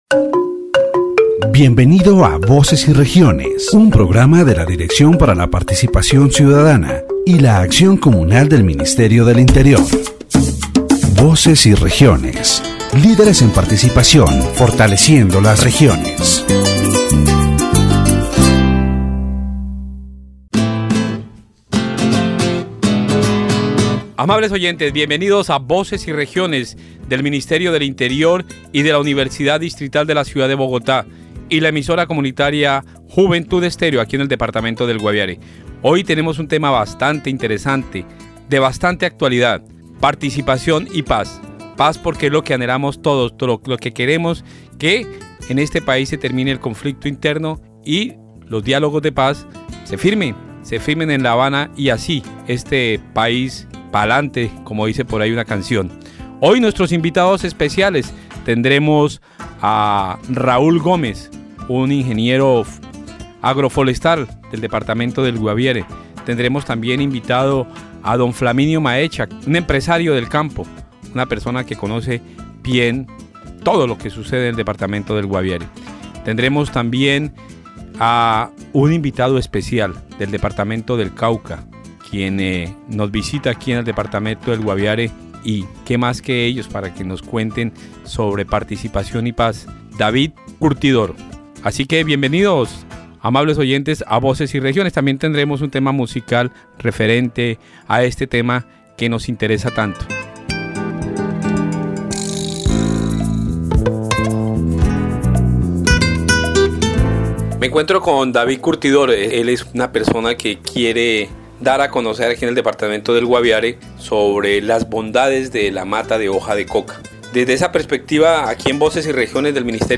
It highlights how farmers in Guaviare have developed initiatives to manufacture products from coca leaves, promoting their consumption as a "plant of life" rather than a "plant that kills." The interviewees stress the importance of changing the perception of the coca leaf, arguing that in the hands of farmers, it can represent an opportunity for development and sustainability in rural communities.